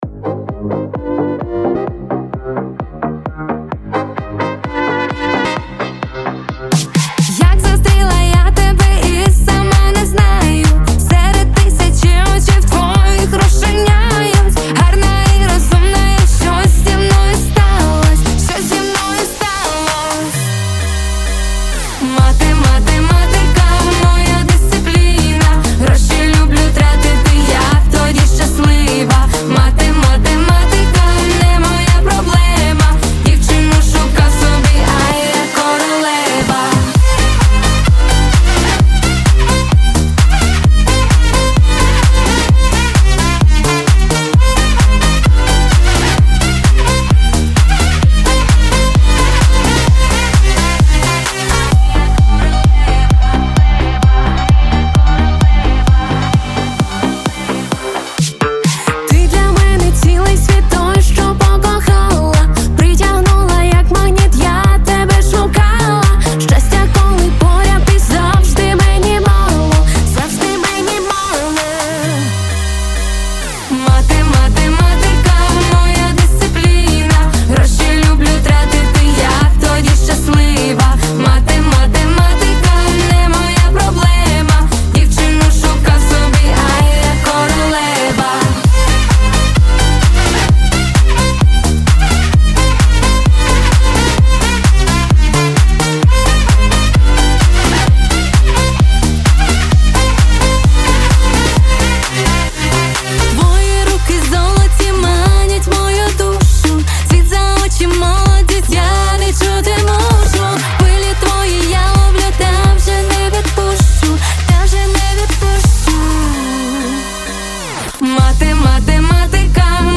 Дівоче тріо